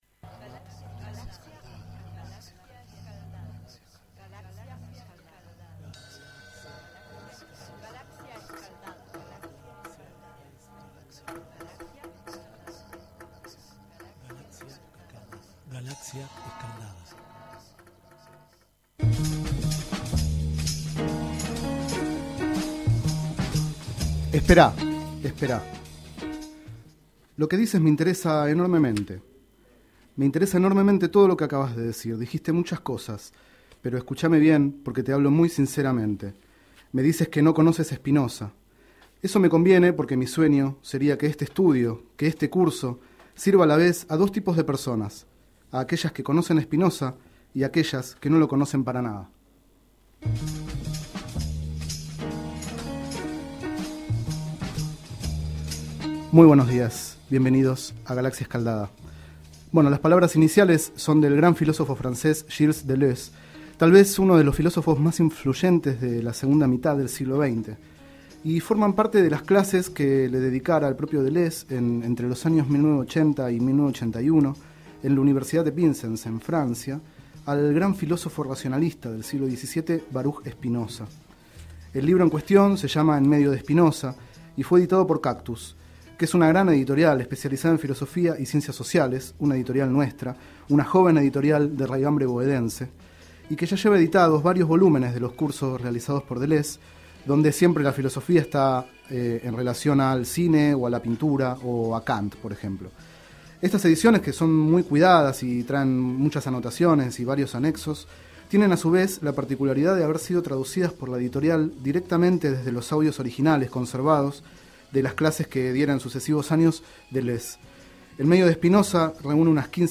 Este es el 25º micro radial, emitido en los programas Enredados, de la Red de Cultura de Boedo, y En Ayunas, el mañanero de Boedo, por FMBoedo, realizado el 03 de septiembre de 2011, sobre el libro En medio de Spinoza, de Gilles Deleuze.
Durante el micro escuchamos de fondo Stopover Bombay, de Alice Coltrane, Journey in Satchidananda (1971).